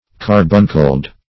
Carbuncled \Car"bun*cled\, a.